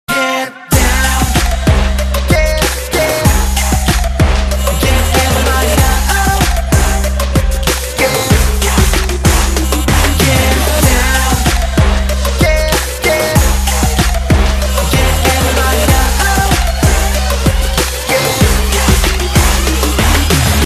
日韩歌曲